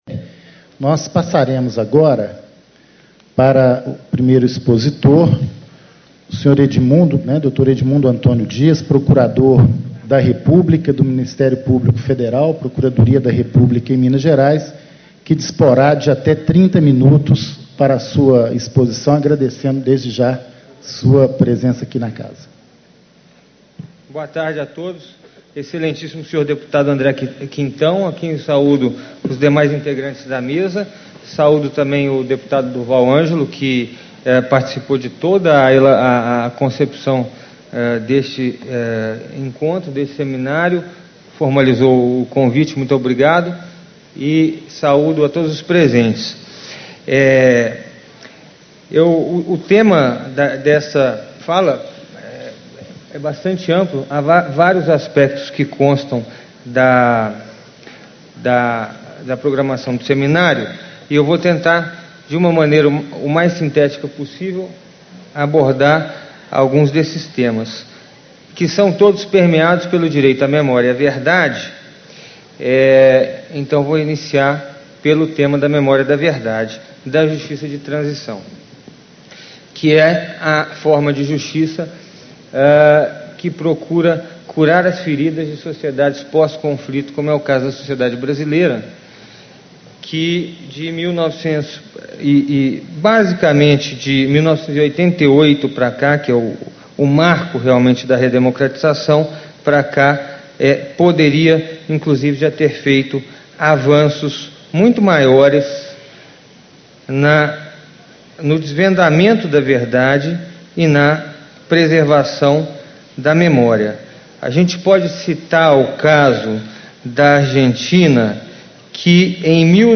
Edmundo Antônio Dias, Procurador da República - Ministério Público Federal - Procuradoria da República em Minas Gerais
Discursos e Palestras